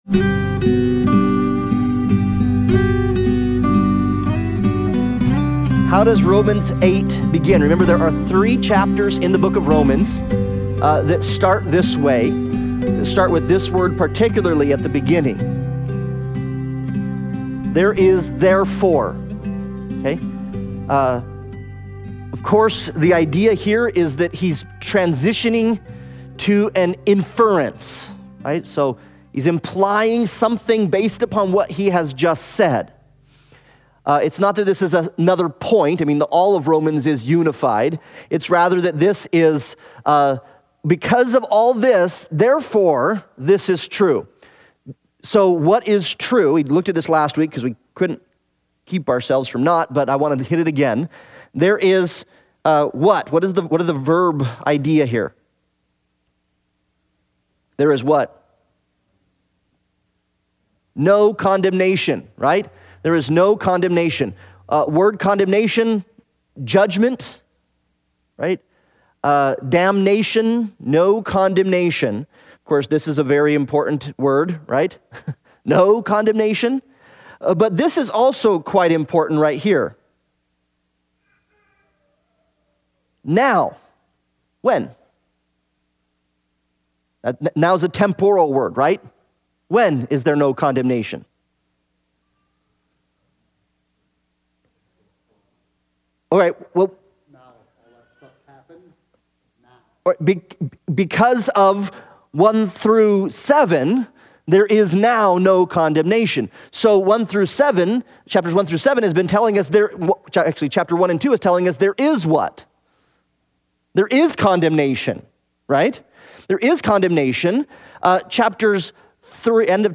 Romans Analysis Passage: Romans 8:1-4 Service Type: Sunday Bible Study « The Glory of the Lord